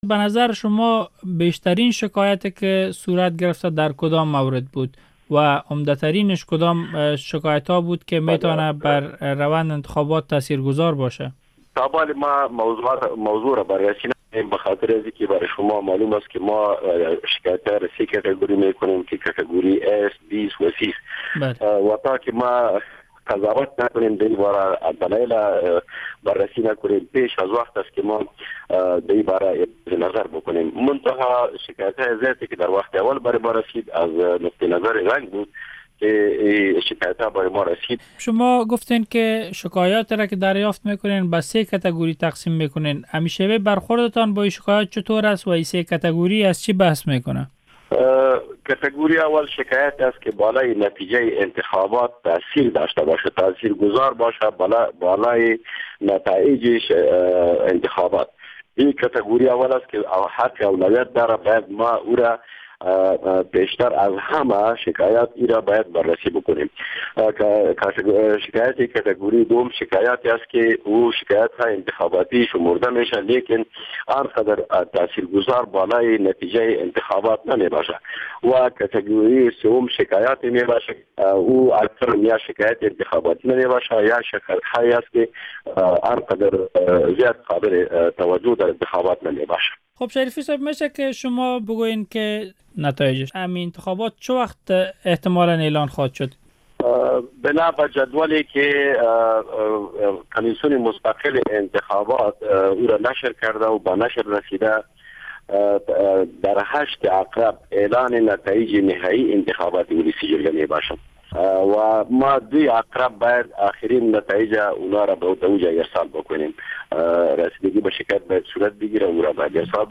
مصاحبه با فضل احمد معنوی در مورد چگونگی رسیده گی به شکایات مردم و کاندیدان